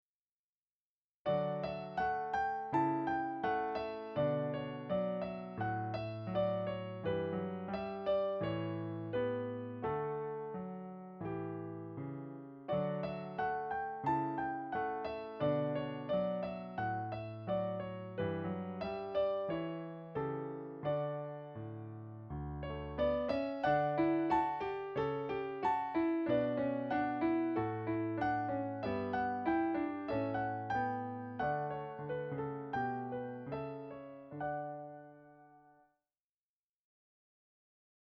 Voicing: 1 Piano 4 Hands